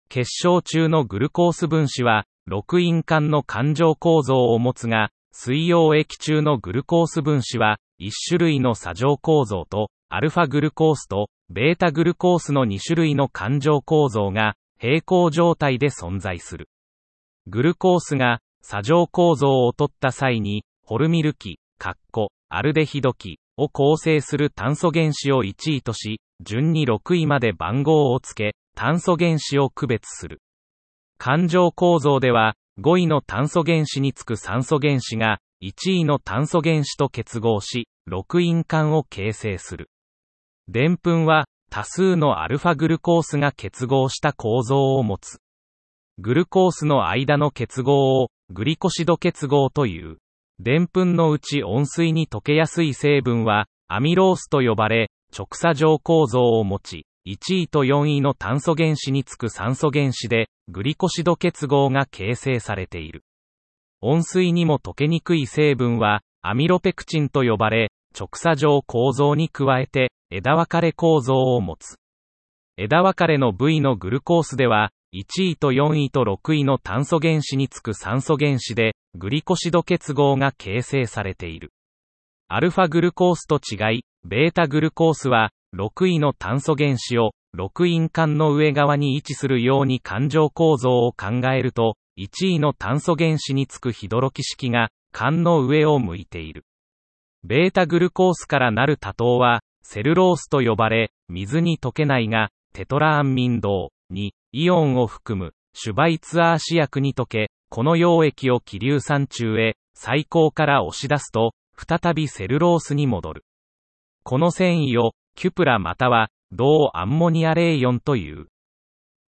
問題文を朗読した音声データは『時間を有効活用したい！』という受験生のための画期的なアイテムです。
※問題文の朗読は、AIが読み上げたものを細かく調整しています。
多少、イントネーションがおかしい部分がありますが、その点はご了承ください。